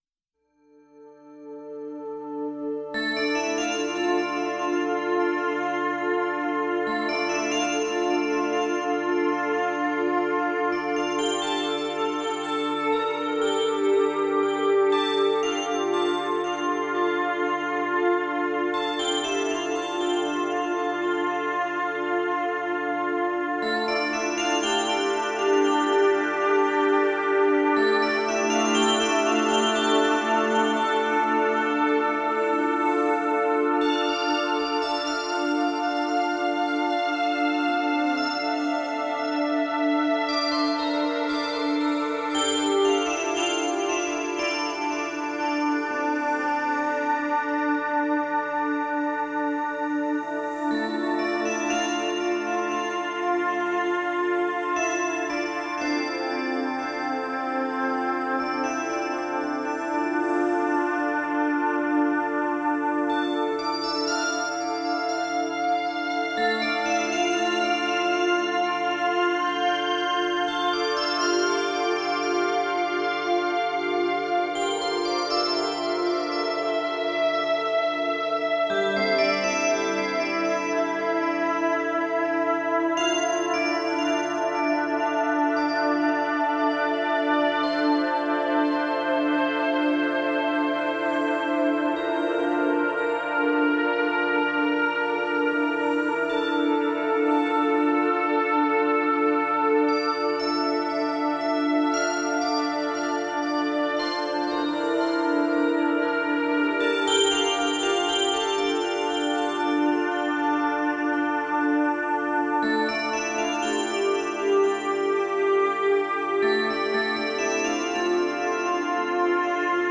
اثری زیبا و شنیدنی از پیانیست با تجربه آمریکایی
نوع آهنگ: لایت]